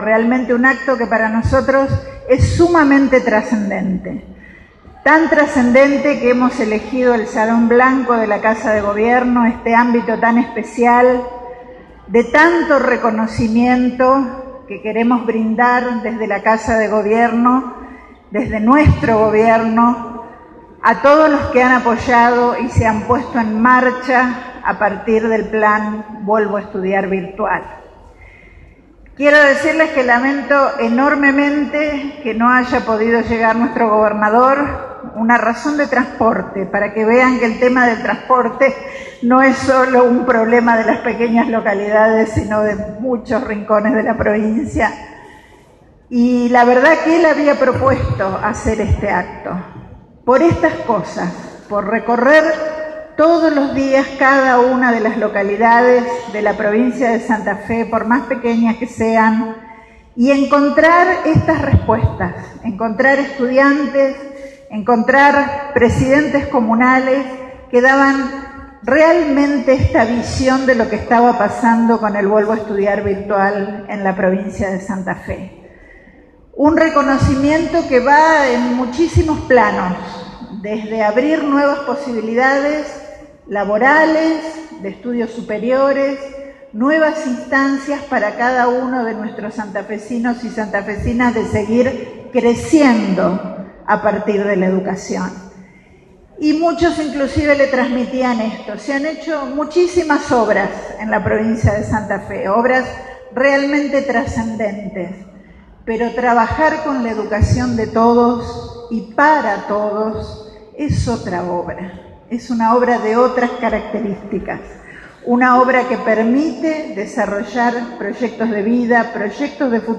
Fue este miércoles, en un acto desarrollado en Casa de Gobierno.
Palabras de Balagué